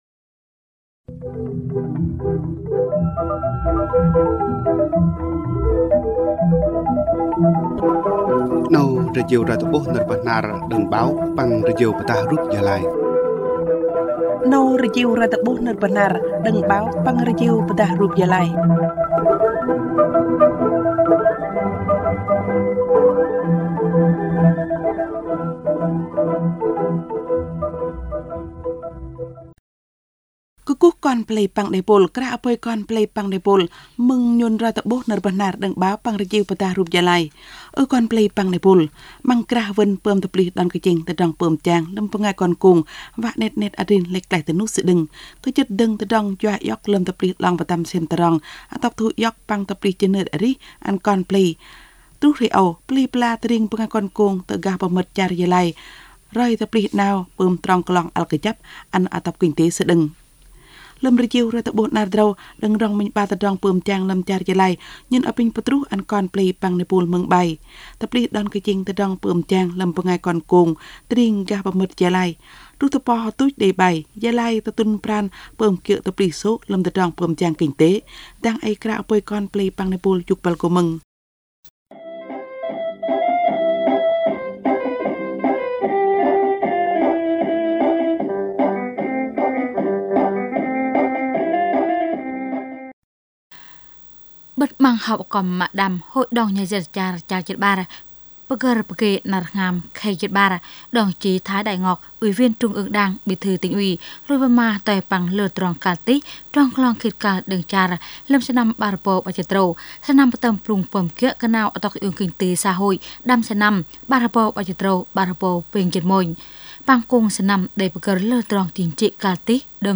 Thời sự PT tiếng Bahnar